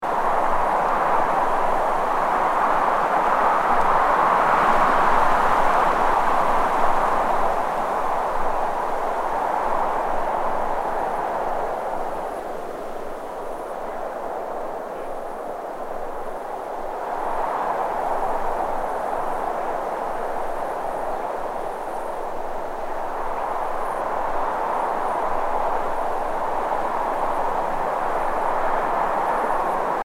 Mp3Bufera di vento2 Bufera di vento
bufera2.mp3